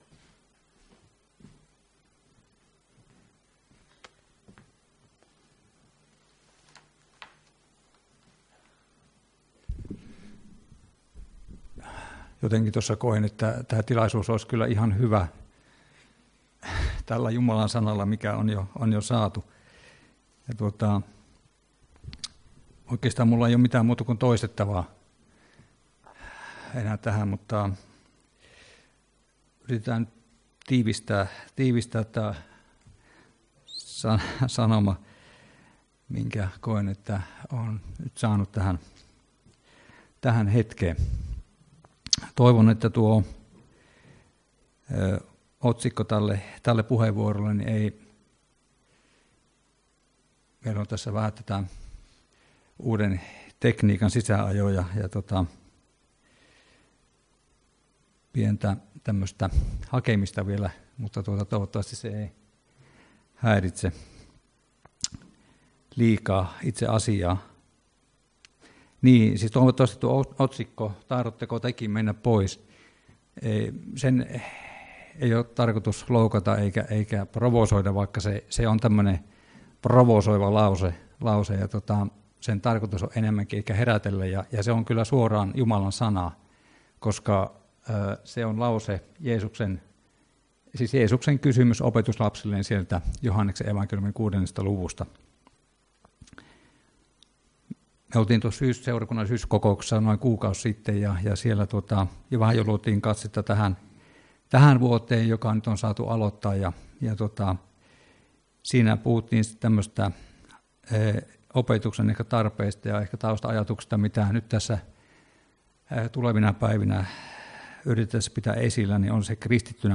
Saarnoja